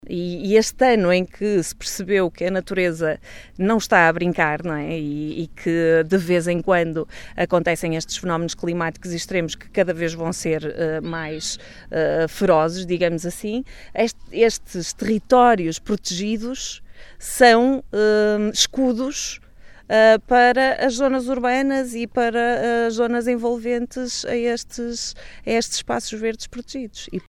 As preocupações foram explanadas no passado sábado, no âmbito de uma conferência de imprensa, na Praia da Ribeira, na albufeira do Azibo, no âmbito do Roteiro do Ambiente, que promoveu pelo distrito de Bragança.